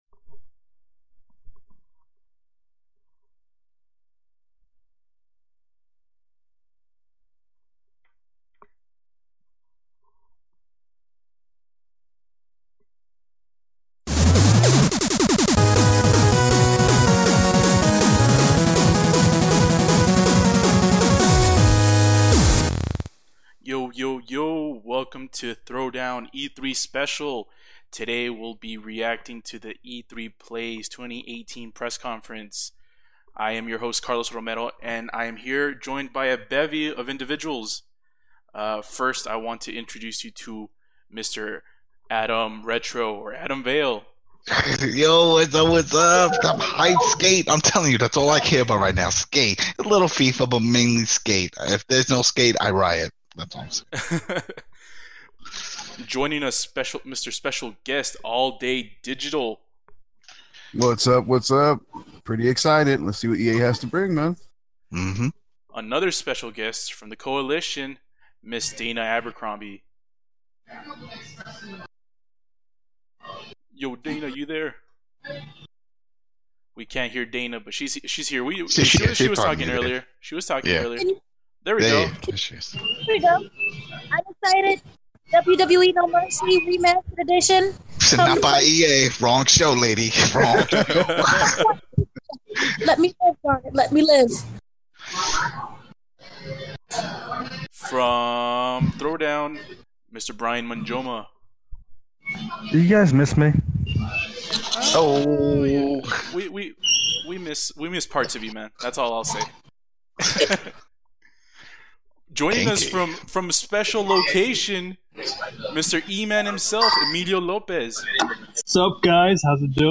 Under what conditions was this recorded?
Throwdown crew covers the first conference of E3 2018, from EA (Electronic Arts). Come and listen to the coverage as we live stream the conference and comment on the latest news and reveals from EA.